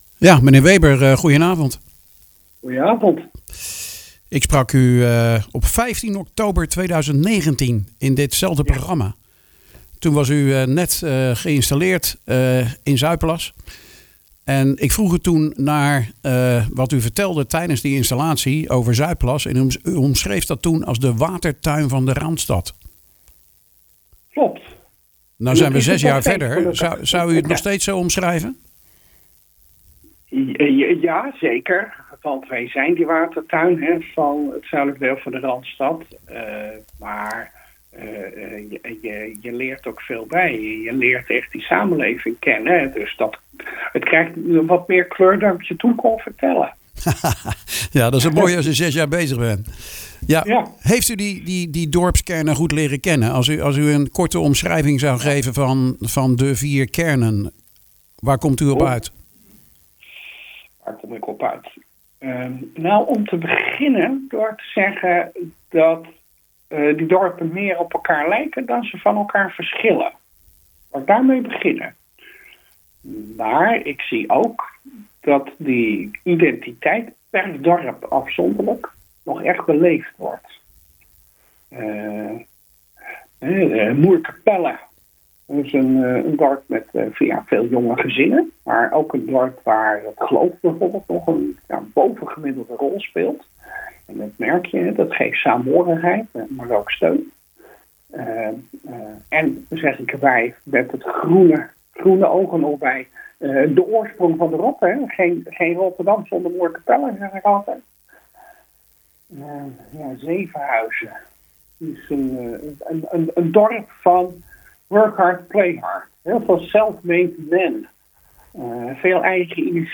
in gesprek met burgemeester Han Weber.